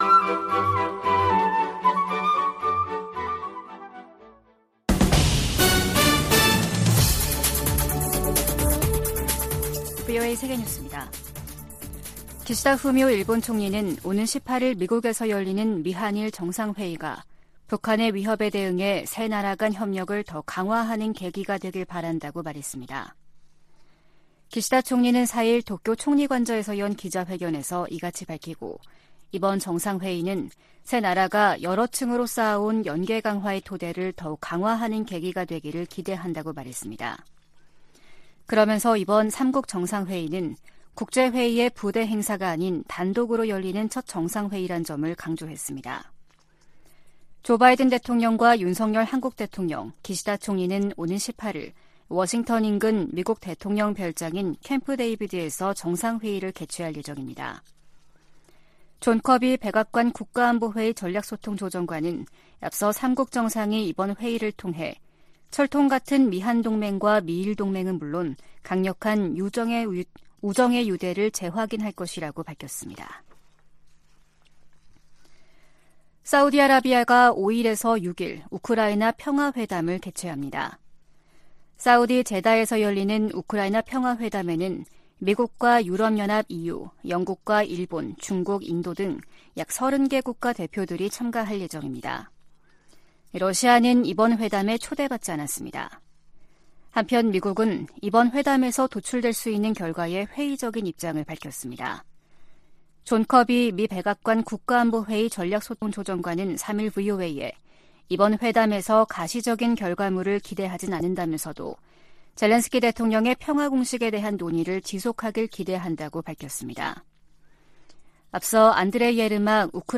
VOA 한국어 아침 뉴스 프로그램 '워싱턴 뉴스 광장' 2023년 8월 5일 방송입니다. 오는 18일 미한일 정상회의에서 북한의 미사일 방어 등 3국 안보 협력을 강화하는 방안이 논의될 것이라고 한국 국가안보실장이 밝혔습니다. 러시아 국방장관의 최근 평양 방문은 군사장비를 계속 획득하기 위한 것이라고 백악관 고위관리가 지적했습니다. 토니 블링컨 미 국무장관은 북한이 월북 미군의 행방과 안위등에 관해 답변을 하지 않았다고 말했습니다.